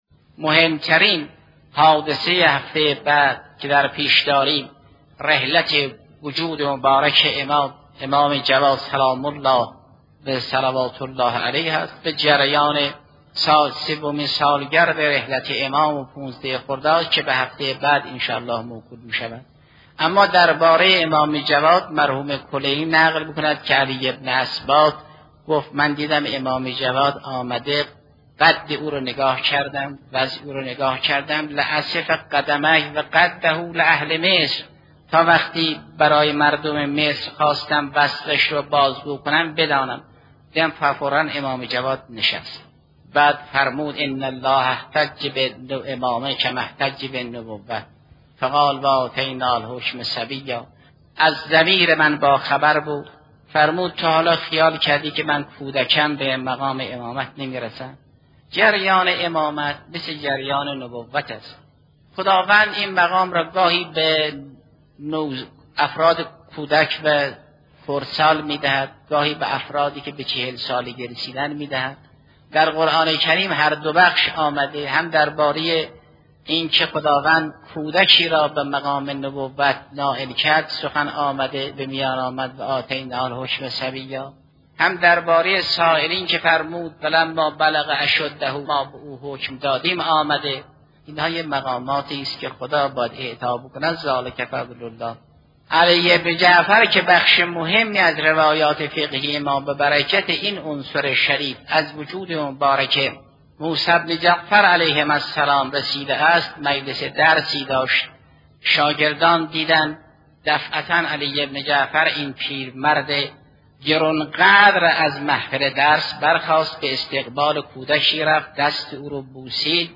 بیانات آیت الله جوادی درباره مقام و منزلت امام جواد علیه السلام